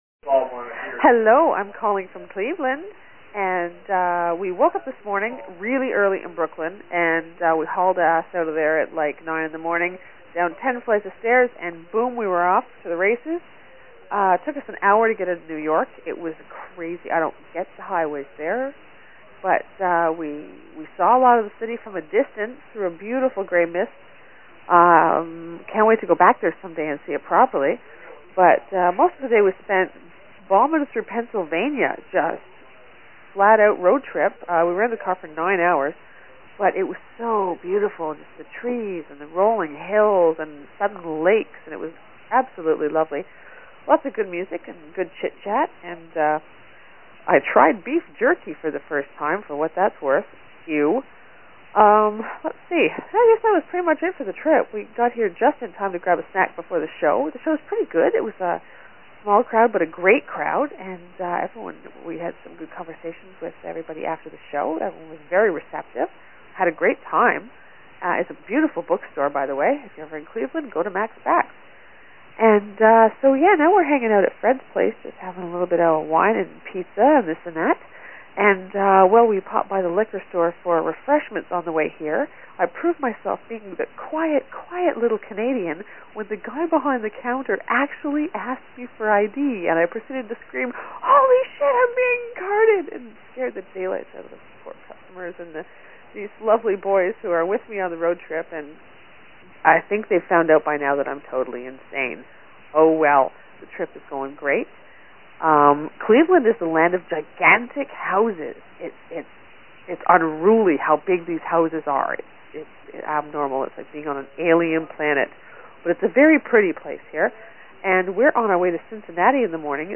Every day they were on the road, one of them called and left a message about something that happened that day, and they were posted below.